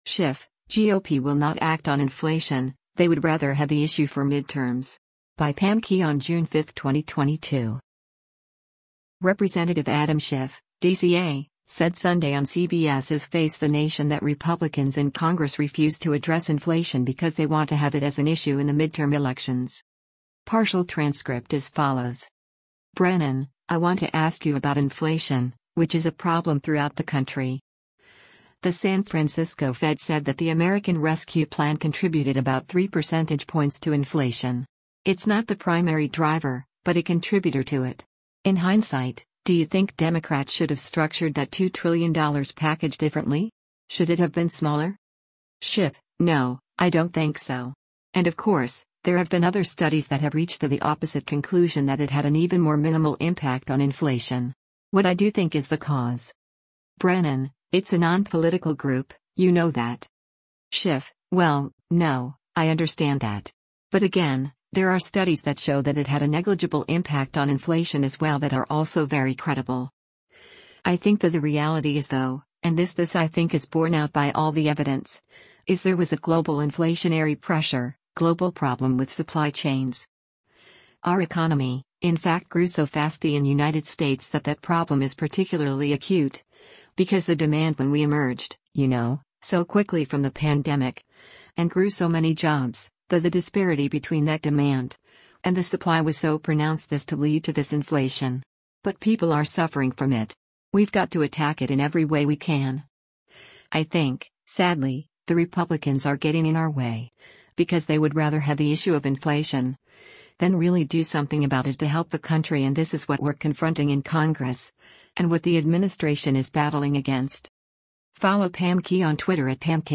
Representative Adam Schiff (D-CA) said Sunday on CBS’s “Face the Nation” that Republicans in Congress refused to address inflation because they want to have it as an issue in the midterm elections.